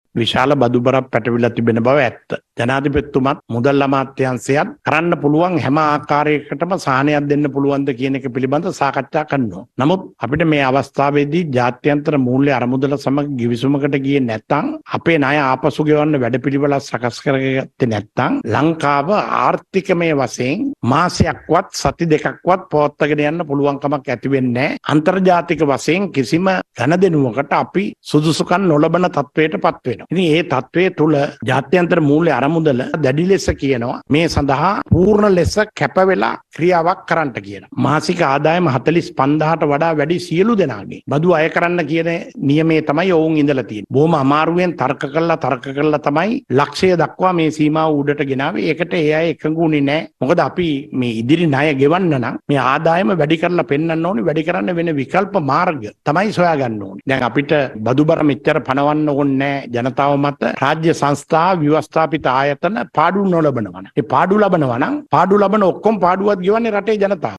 මේ ඒ පිළිබදව වැඩිදුරටත් කරුණු පැහැදිලි කළ කැබිනට් අමාත්‍ය බන්ධුල ගුණවර්ධන මහතා.
අද පැවති කැබිනට් තීරණ දැනුම් දීමේ මාධ්‍ය හමුවේදී බන්ධුල ගුණවර්ධන මහතා මේ බව සඳහන් කළා.